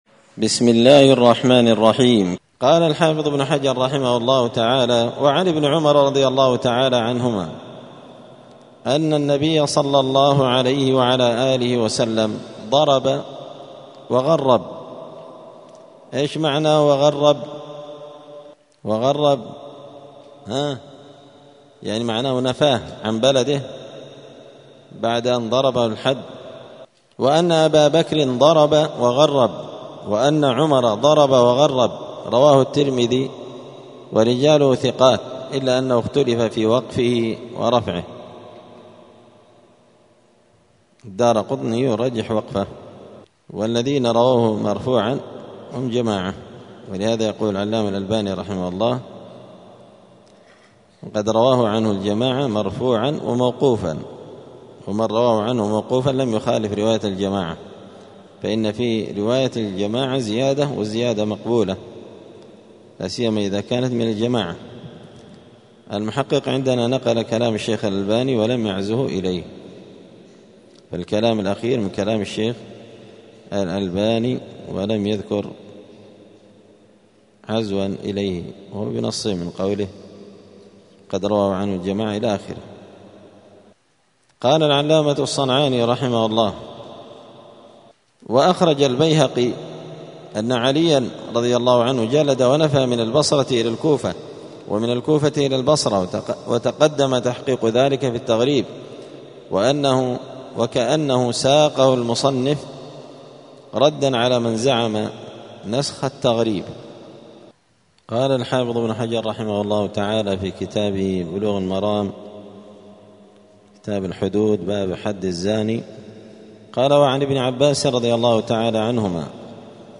*الدرس الحادي عشر (11) {باب تشبه الرجال بالنساء والعكس}*